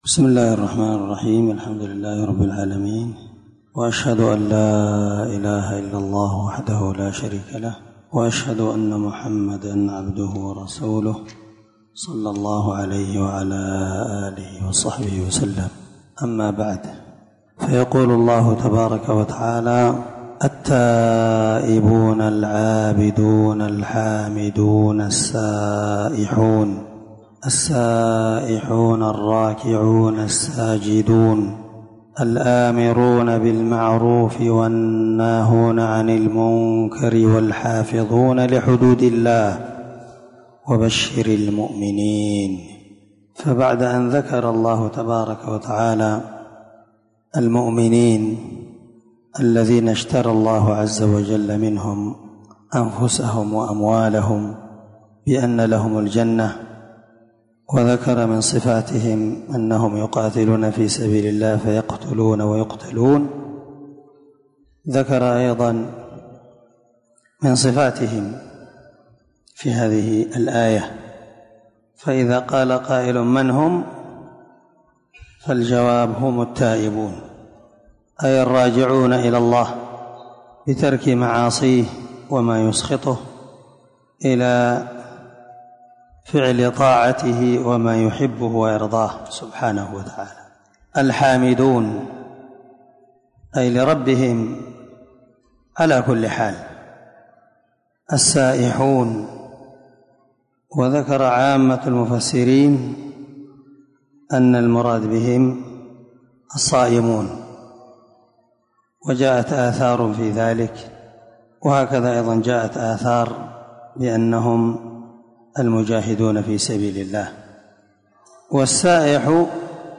576الدرس47تفسير آية ( 112) من سورة التوبة من تفسير القران الكريم مع قراءة لتفسير السعدي